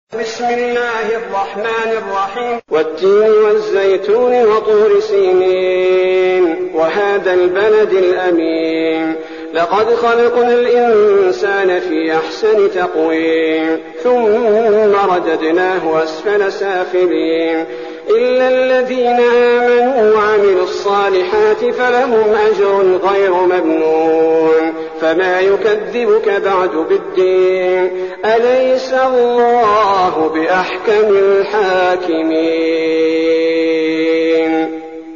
المكان: المسجد النبوي الشيخ: فضيلة الشيخ عبدالباري الثبيتي فضيلة الشيخ عبدالباري الثبيتي التين